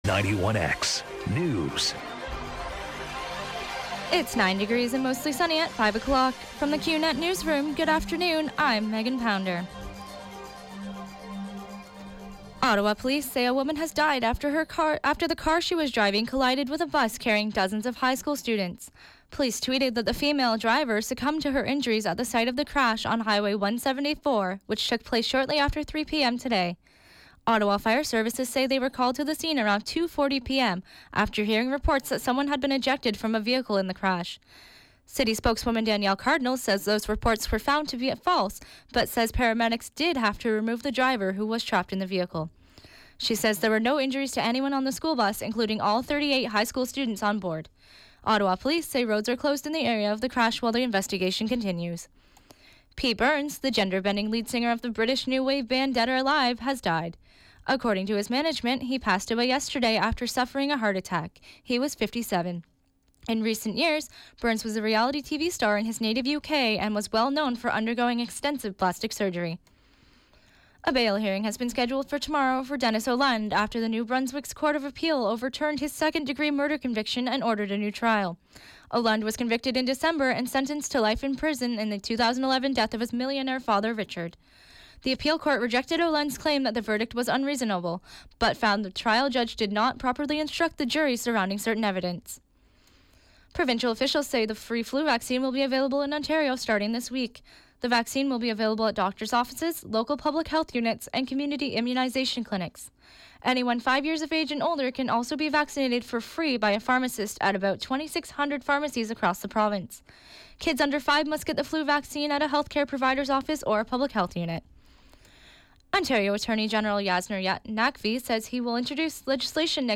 91X FM Newscast – Monday, Oct. 24, 2016, 5 p.m.